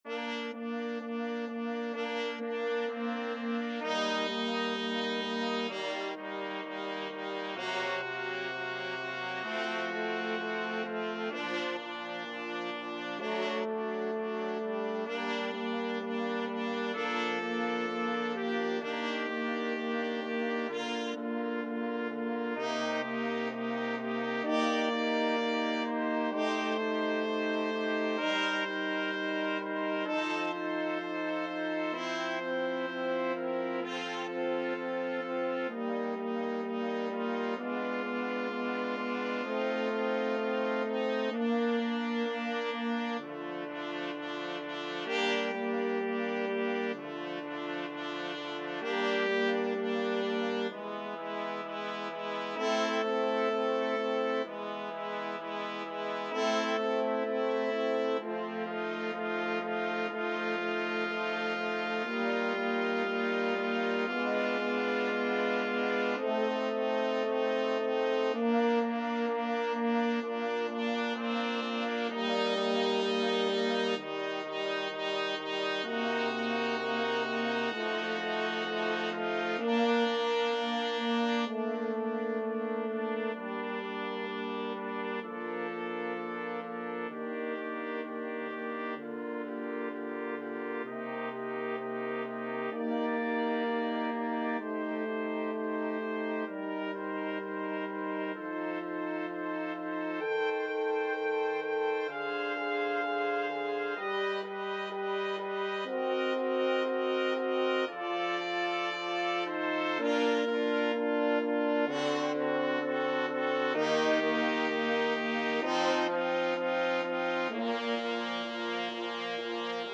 Trumpet 1Trumpet 2French Horn 1French Horn 2
4/4 (View more 4/4 Music)
Nicht zu schnell = 64 Nicht zu schnell
Brass Quartet  (View more Intermediate Brass Quartet Music)
Classical (View more Classical Brass Quartet Music)